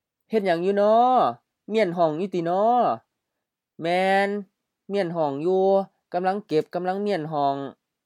Vocabulary recordings — เมี้ยน 6